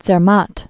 (tsĕr-mät)